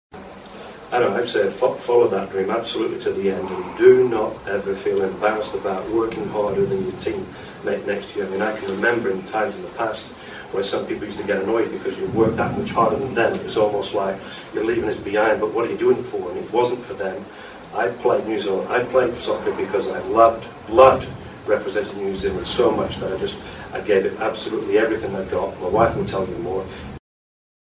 These sound tracks were produced from videos made at each of the Peak Performance Seminars.
Video and audio quality is not good for all events due to local venue conditions.